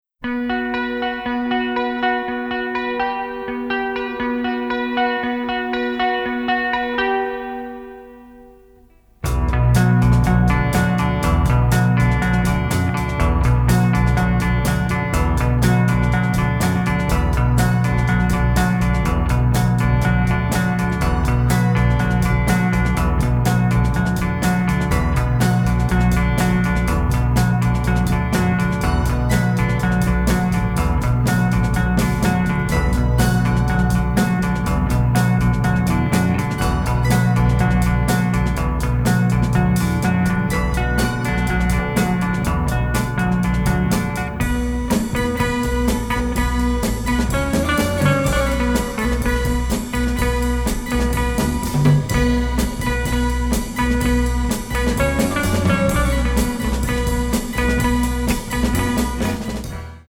Italian masterpiece of psychedelia!